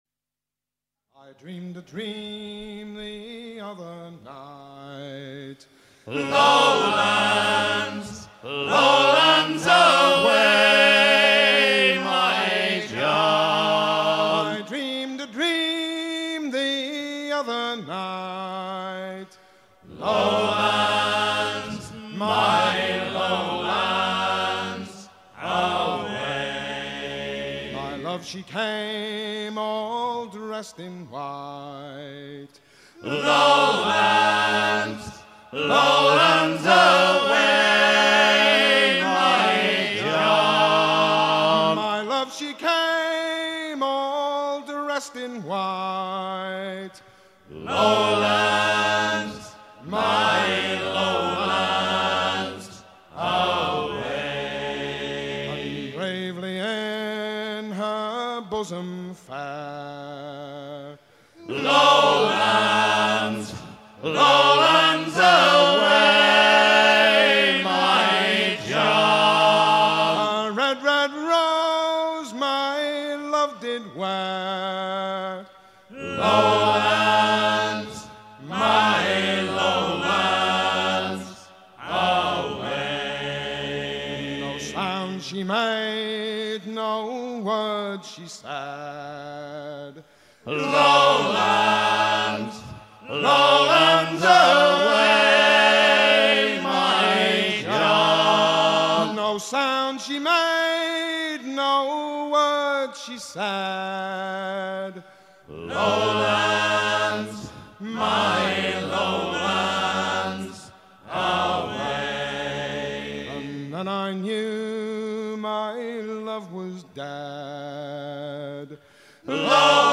shanty à pomper
Pièce musicale éditée